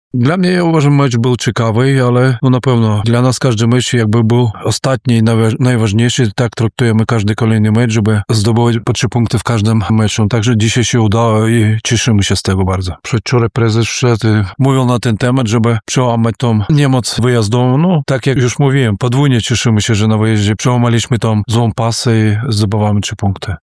Komentował po ostatnim spotkaniu trener